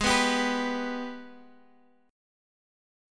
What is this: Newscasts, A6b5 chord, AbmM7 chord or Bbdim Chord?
AbmM7 chord